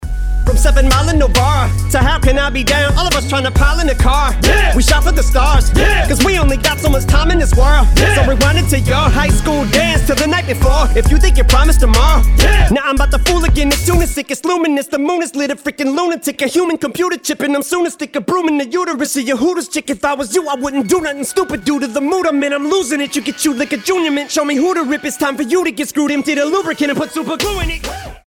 • Качество: 320, Stereo
мужской вокал
Хип-хоп
качающие
речитатив